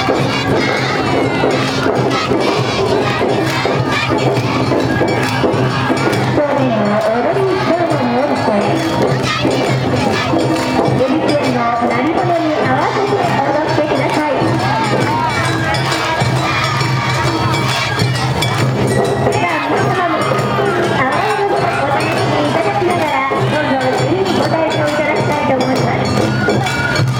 −　阿波踊り　−
街中ではどこからともなく阿波踊りの音楽が